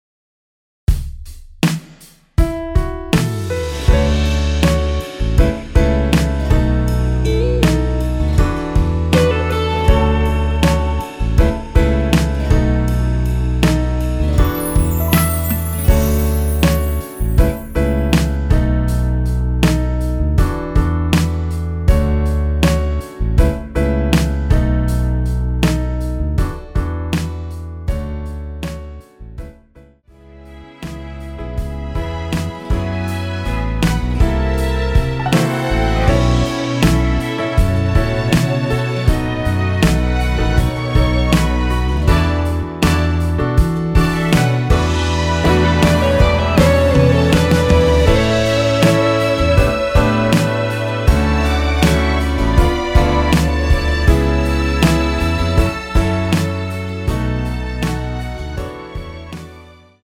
원키에서 (+5)올린 MR 입니다.(미리듣기 확인)
앞부분30초, 뒷부분30초씩 편집해서 올려 드리고 있습니다.
중간에 음이 끈어지고 다시 나오는 이유는